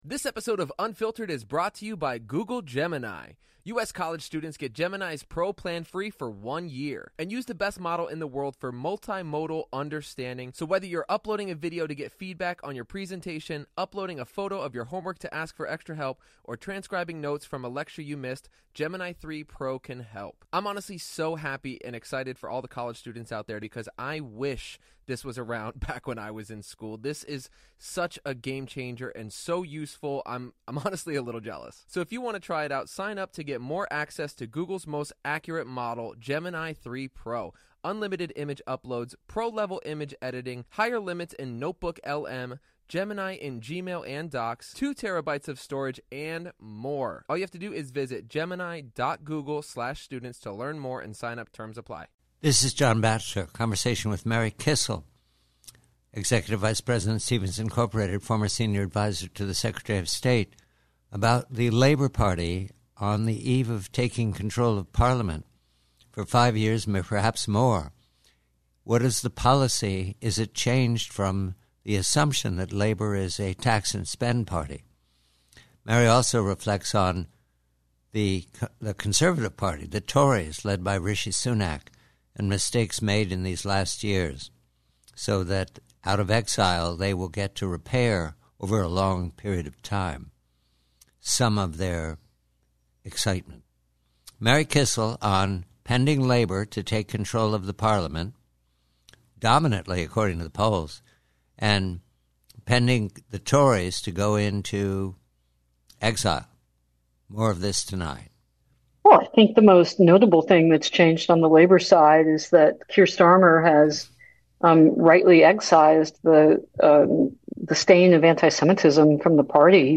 PREVIEW: UK ELECTION: Conversation